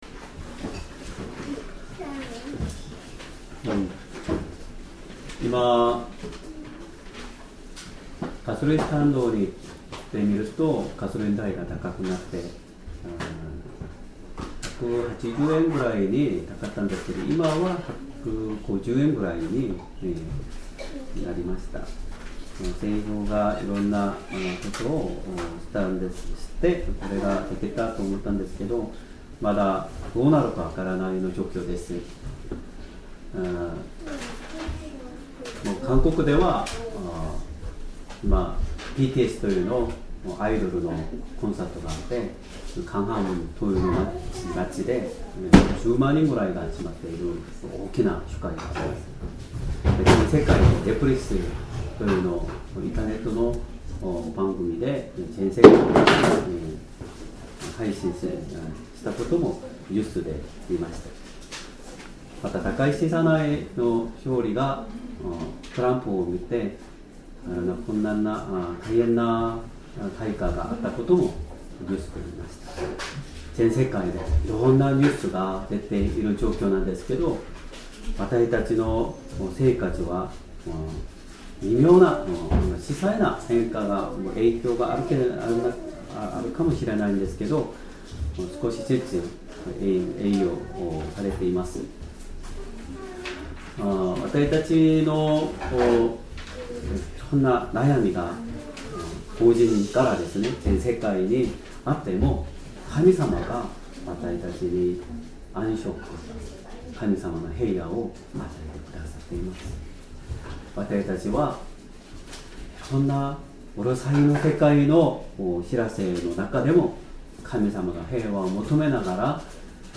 Sermon
2026年3月22日 主日礼拝 説教 「人の子は安息日の主です」 聖書 マタイの福音書12章1～15節 12:1 そのころ、イエスは安息日に麦畑を通られた。